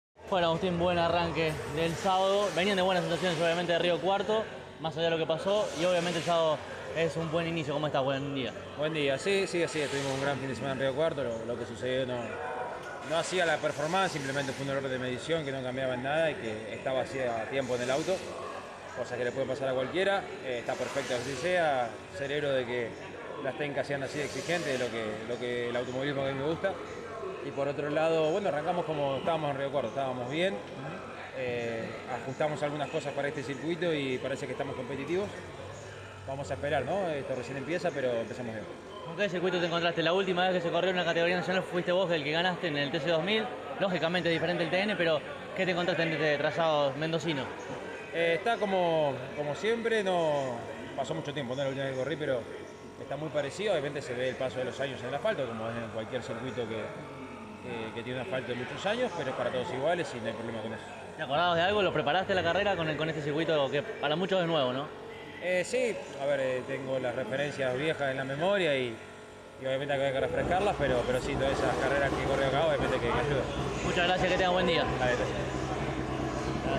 Así entonces, esta es la palabra de Canapino tras el entrenamiento, en diálogo con CÓRDOBA COMPETICIÓN: